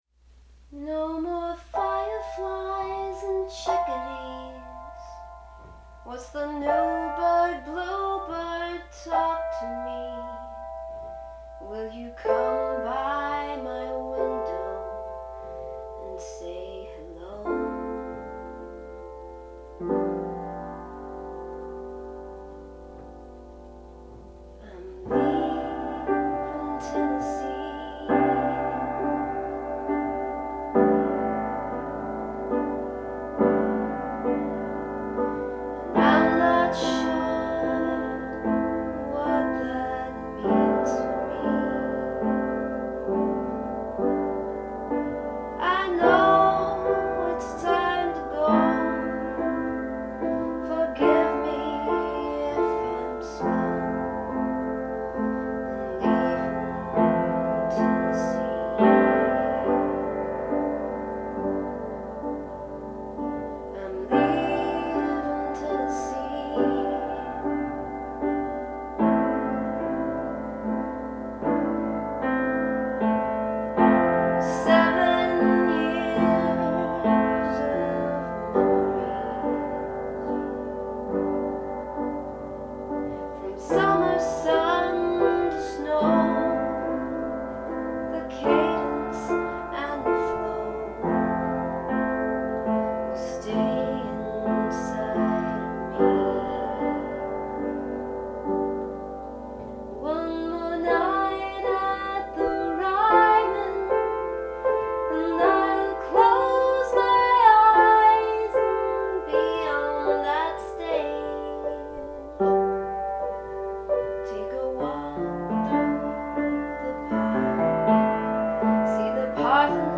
But I do remember those first pangs of letting go, when I sat down at the piano and meandered my way through a new song. Here’s a live recording of
It’s unpolished and tentative; some of the words are hard to hear, but the emotion and sentiment are definitely clear.